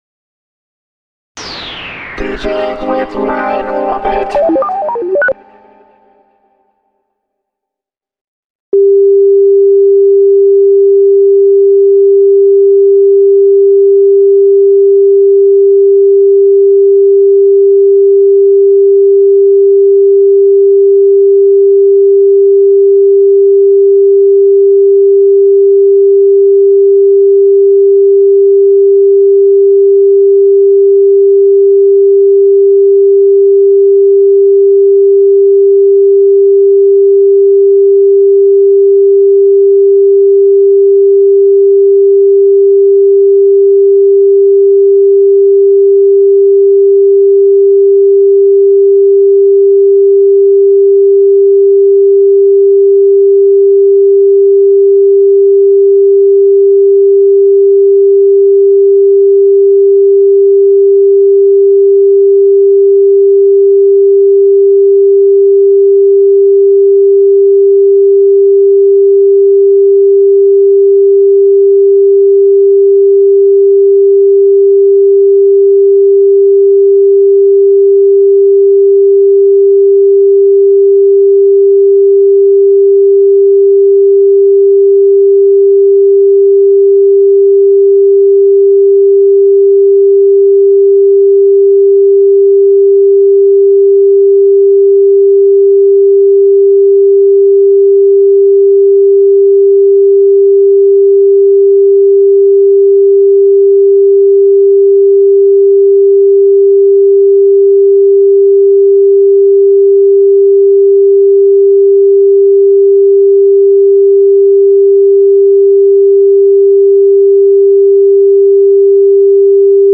Sine Wave 396Mz 5:00 mono Sine Wave 417Mz 5:00 mono Sine Wave 528Hz 5:00 mono Sine Wave 639Hz 5:00 mono Sine Wave 741Hz 5:00 mono Sine Wave 852Hz 5:00 mono Sine Wave 963Hz 5:00 mono